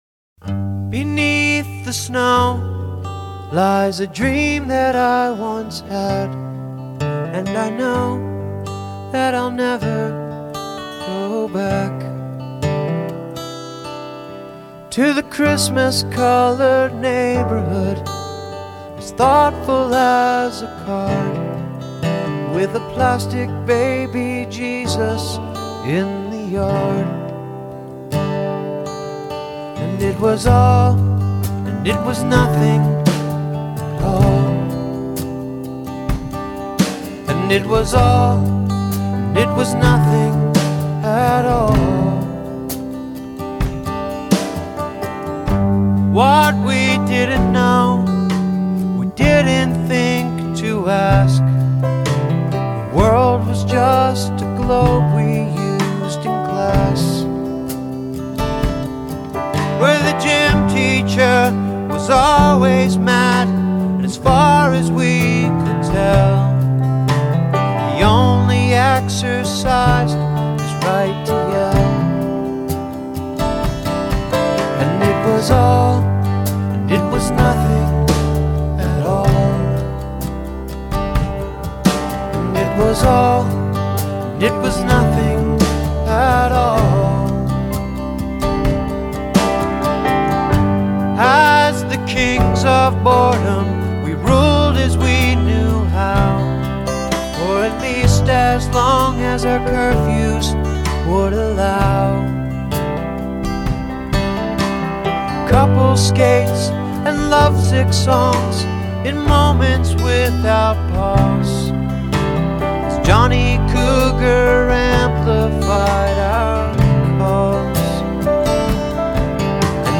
arty power pop